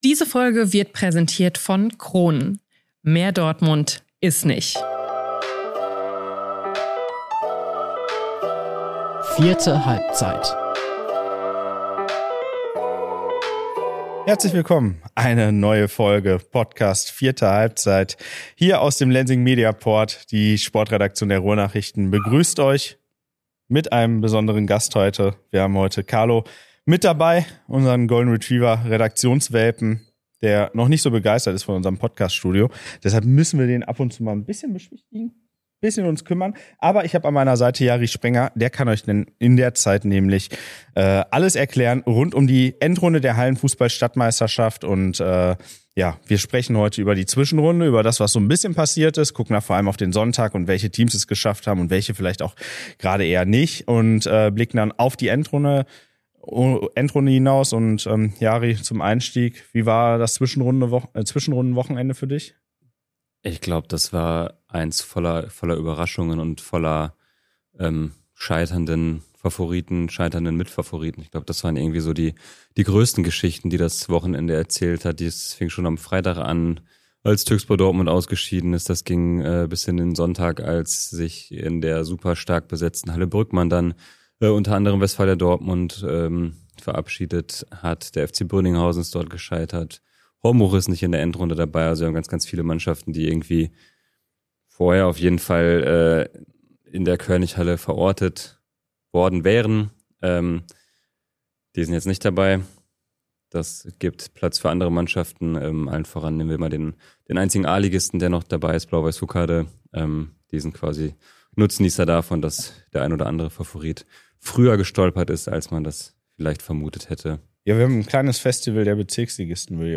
Und auch ein Special-Guest meldet sich.